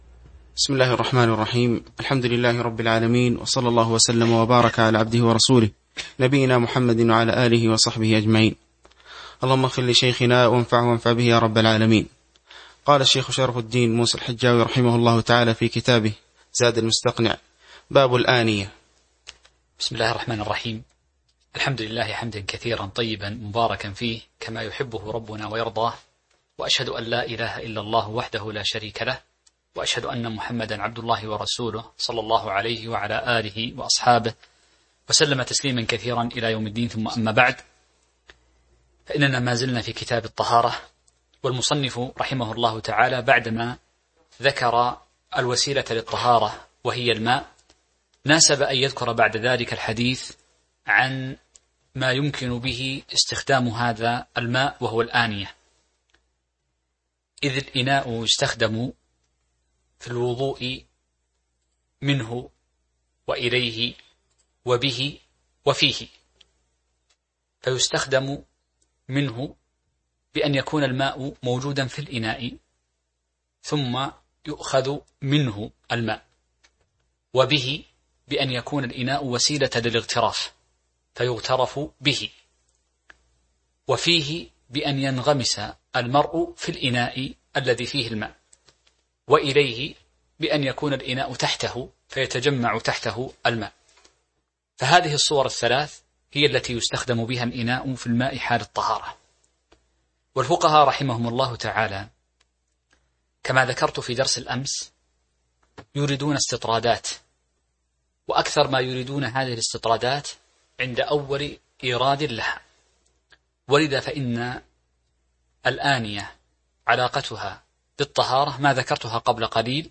تاريخ النشر ١٧ ذو الحجة ١٤٤٢ هـ المكان: المسجد النبوي الشيخ